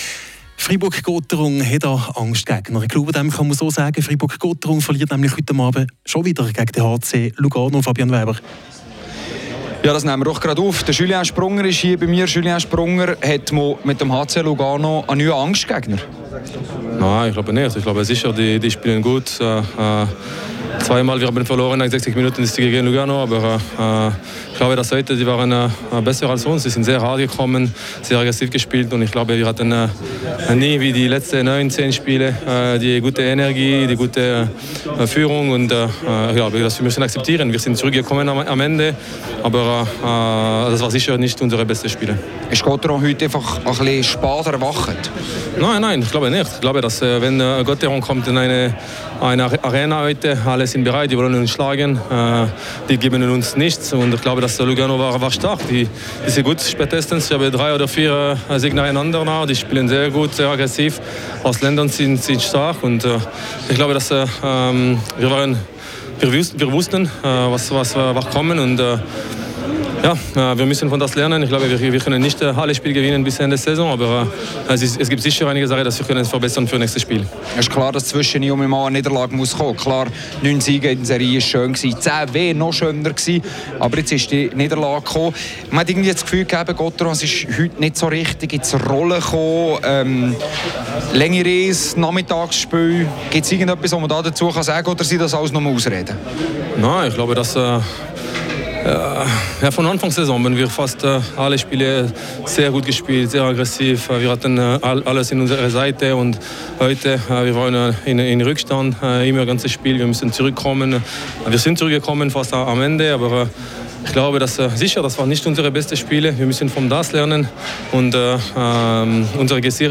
Spielanalyse